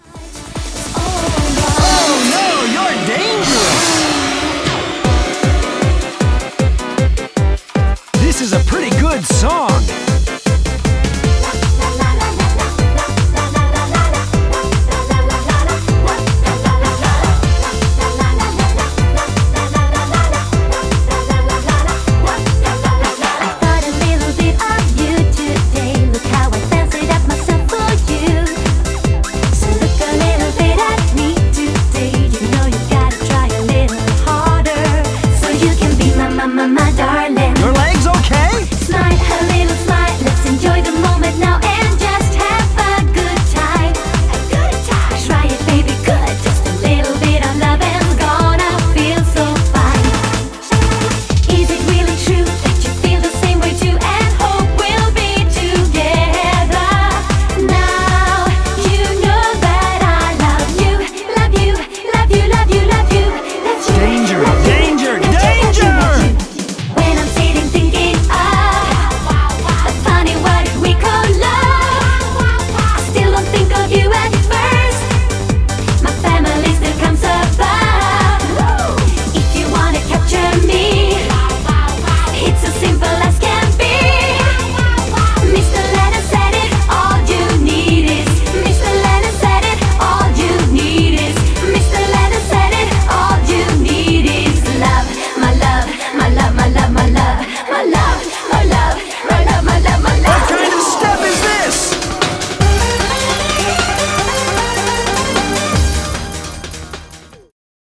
BPM155-155
Audio QualityCut From Video